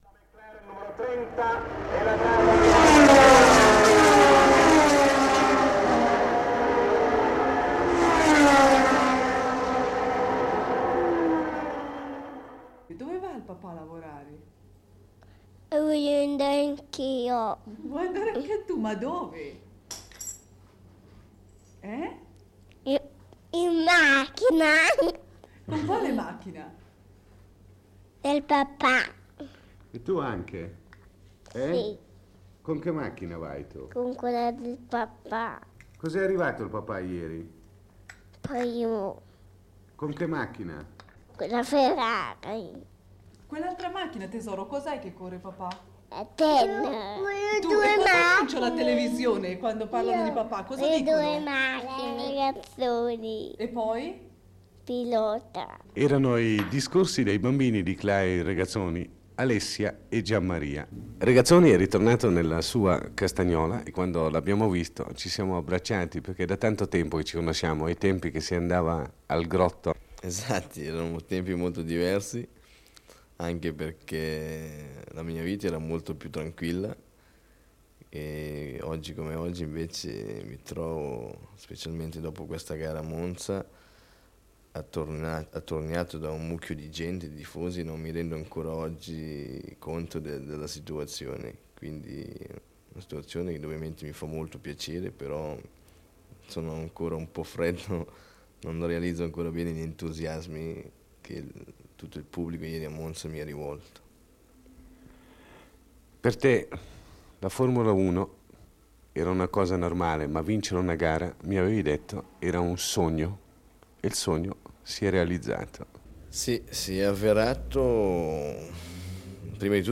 Intervista a Clay Regazzoni, rientrato nella sua casa di Castagnola all'indomani dalla vittoria nel Gran Premio di Formula 1 di Monza, la prima in carriera.